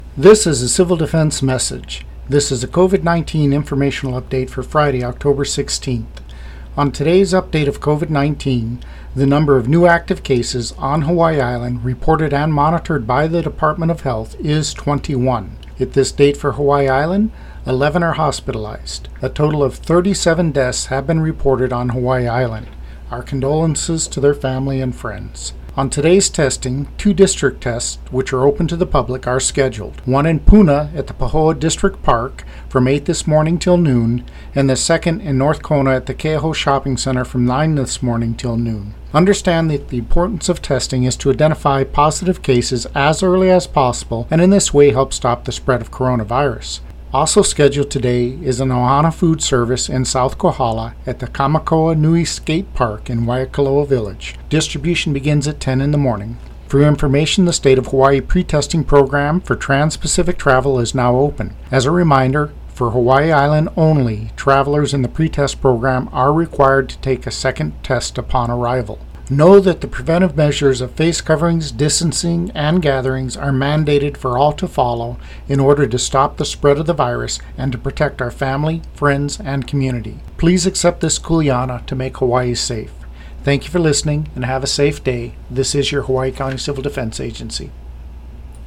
UPDATE (10:30 a.m.) – From the Hawaiʻi County Civil Defense morning radio message: